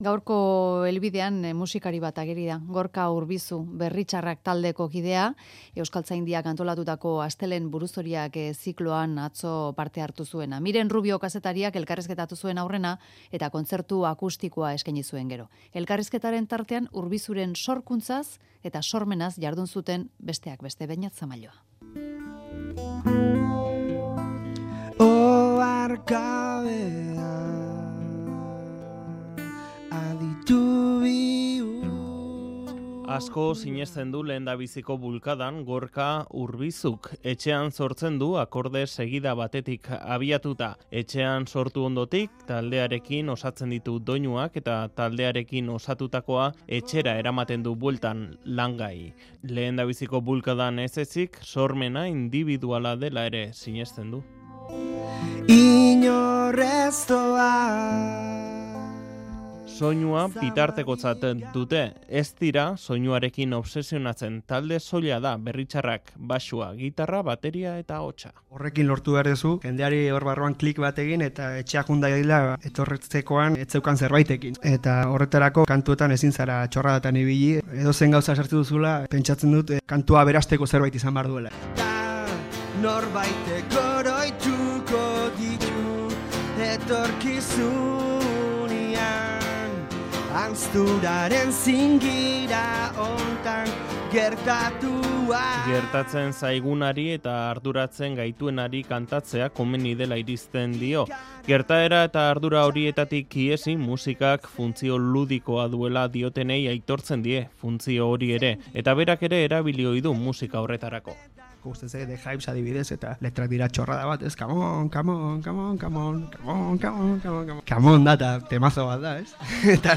Elkarrizketa eta emanaldia.
Kontzertu akustikoaz emateaz gain, sorkuntzaz eta sormenaz aritu zen hizketan.